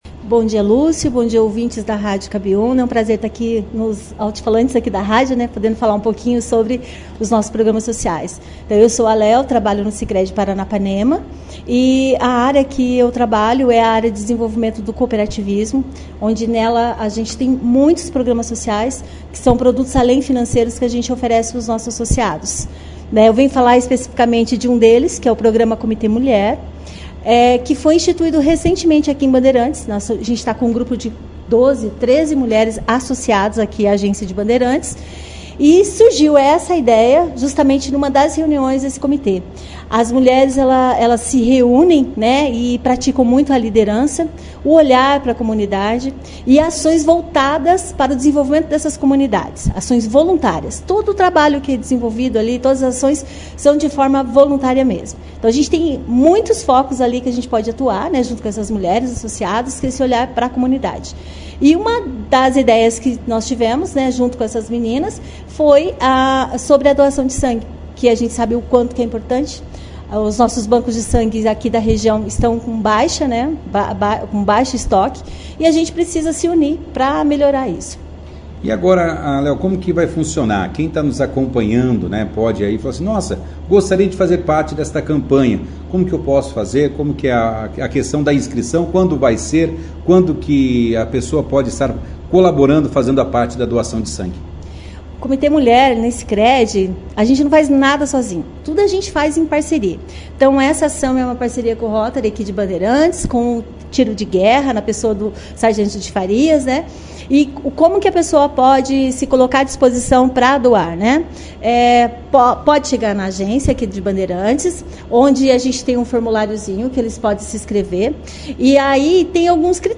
participaram da edição do Jornal Operação Cidade desta quarta-feira, 15/03, falando sobre a campanha.